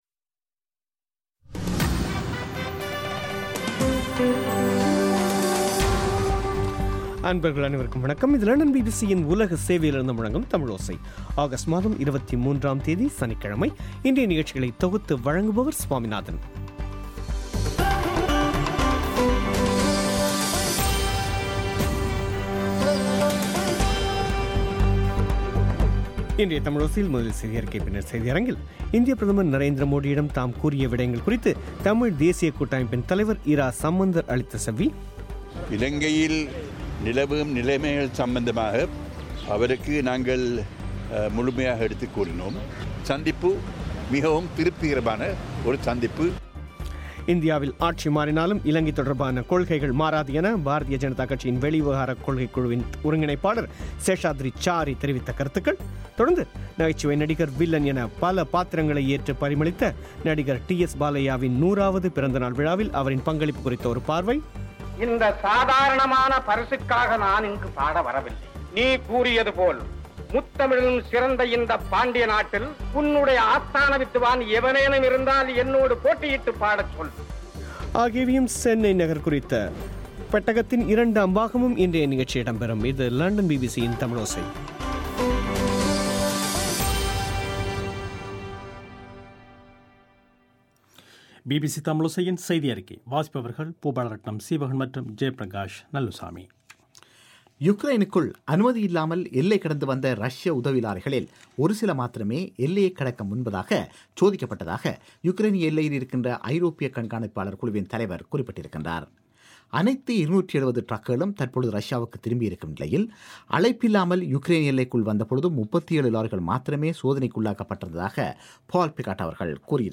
• இந்தியப் பிரதமர் நரேந்திர மோடியிடம் தாம் கூறிய விடயங்கள் குறித்து தமிழ் தேசியக் கூட்டமைப்பின் தலைவர் இரா சம்பந்தர் அளித்த செவ்வி,